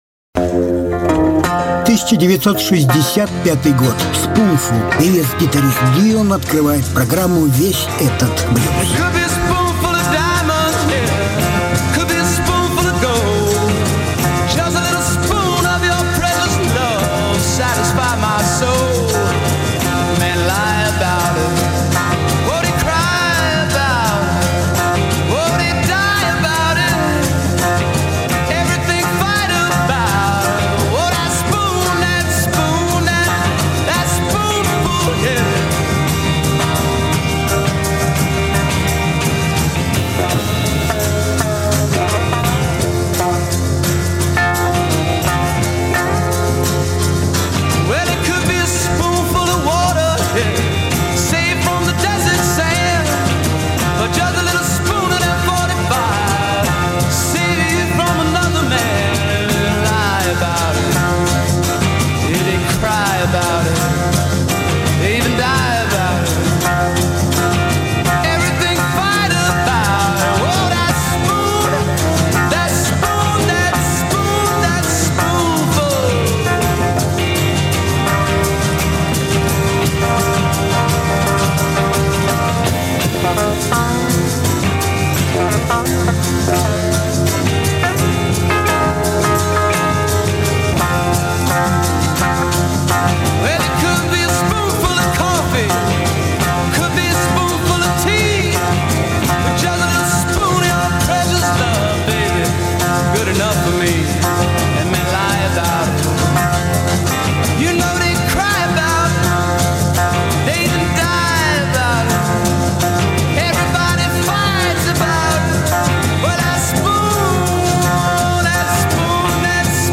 Жанр: Блюзы и блюзики
Lead Guitar
Harmonica
Slide Guitar, Alto Vocals, Bass, Guitar